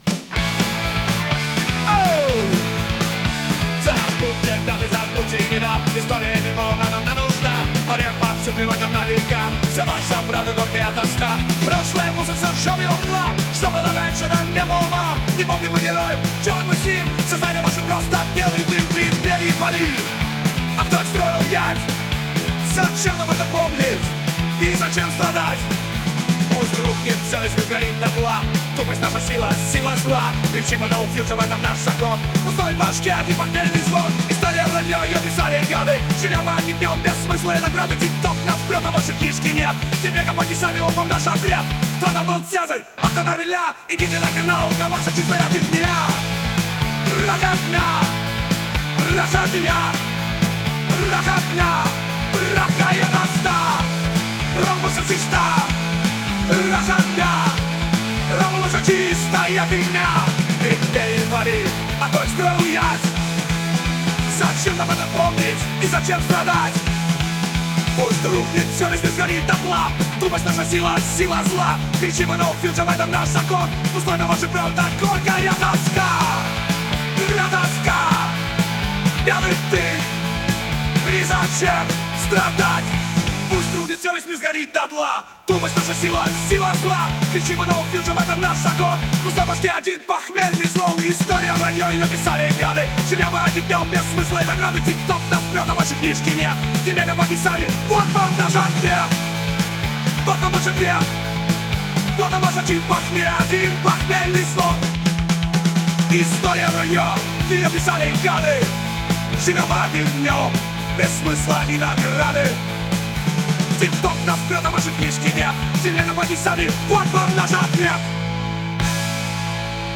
Иллюстрации к "Песни в стиле Punk"
• Песня: Пародии